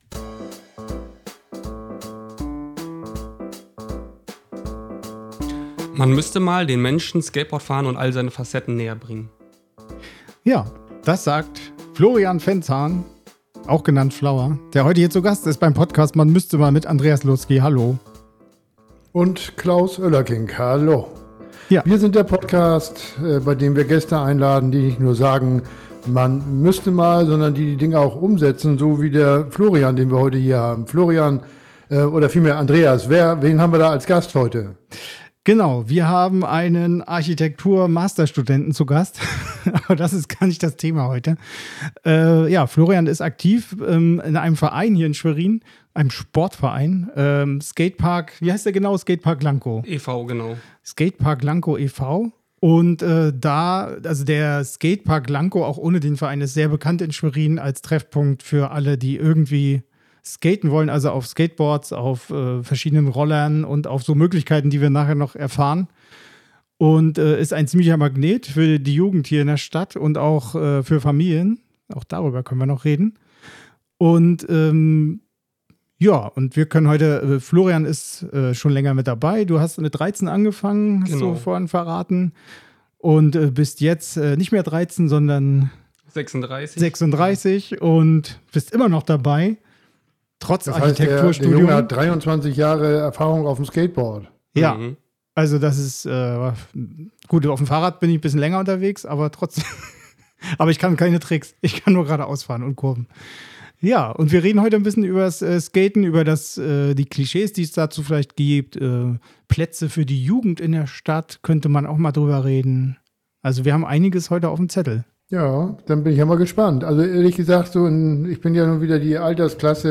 In unserem Gespräch erzählt er, worauf es beim Kauf eines Skatbords ankommt. Worauf muss ein Einsteiger achten?